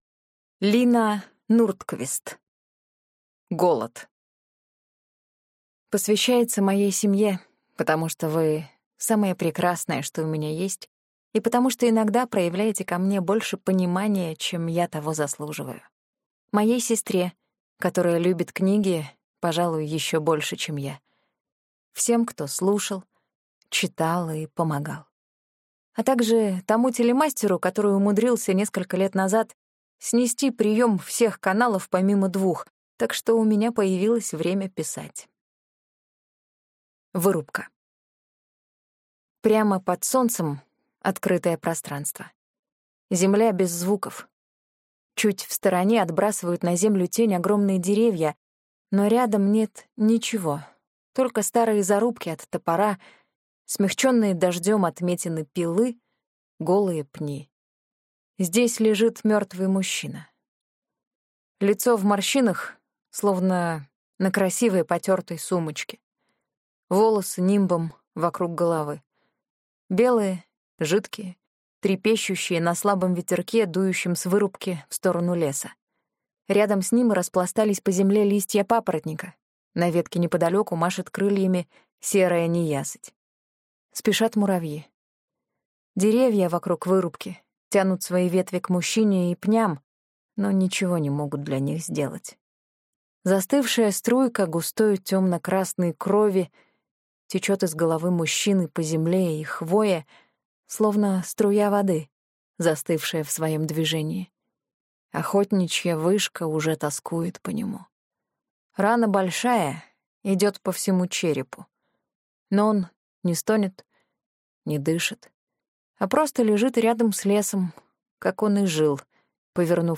Аудиокнига Голод | Библиотека аудиокниг